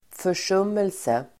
Ladda ner uttalet
försummelse substantiv, neglect Uttal: [för_s'um:else]
f0366rsummelse.mp3